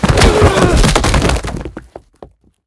Tackle2.wav